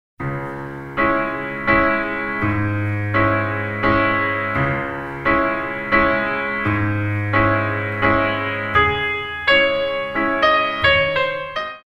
Music is mainly selected from classical ballet repertoire.